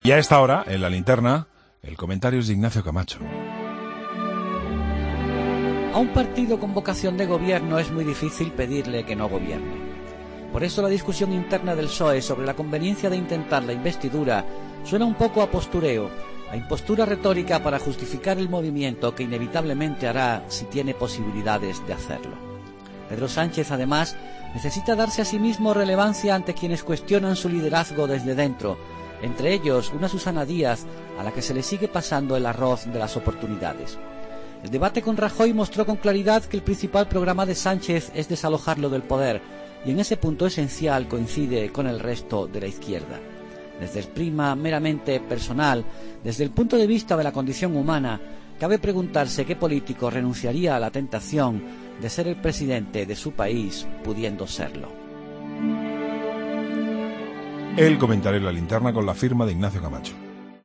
Ignacio Camacho dedica su comentario a los pactos que se presentan necesarios tras los resultados electorales del domingo.